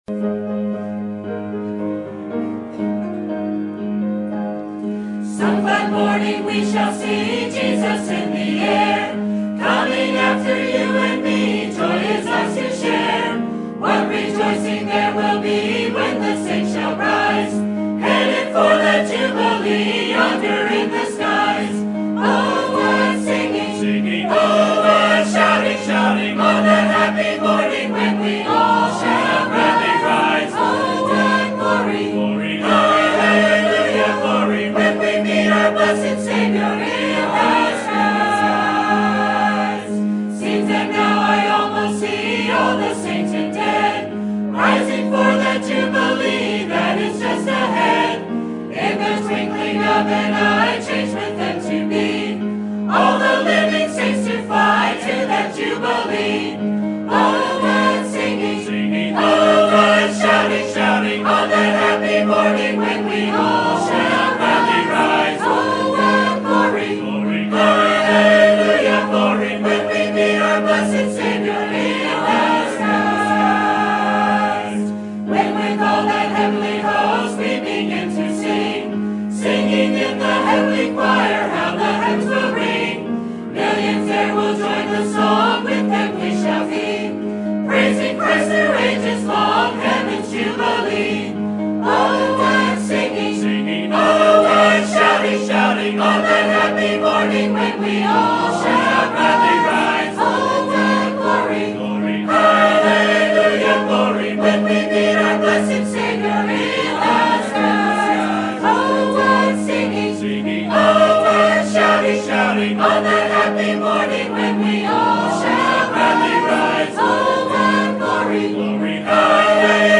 Sermon Topic: Winter Revival Sermon Type: Special Sermon Audio: Sermon download: Download (24.8 MB) Sermon Tags: Matthew Prayer Iniquity Ready